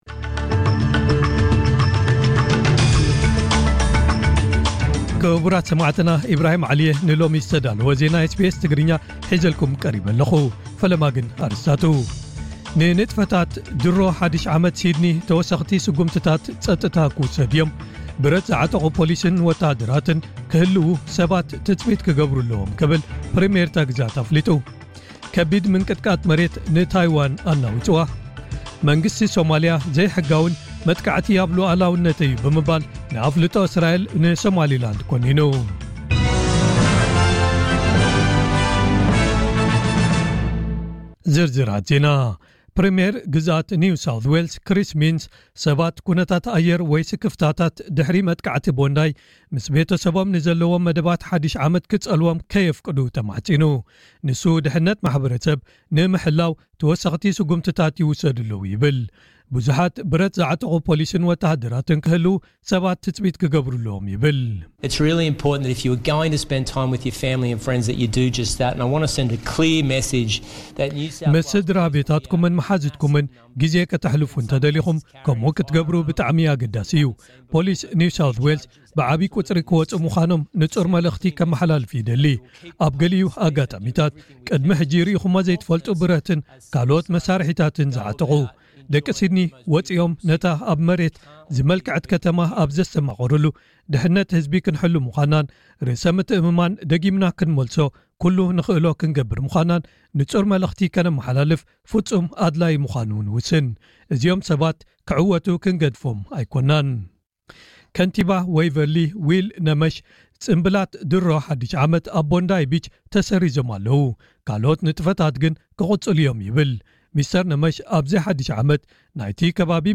ዕለታዊ ዜና ኤስቢኤስ ትግርኛ (29 ታሕሳስ 2025)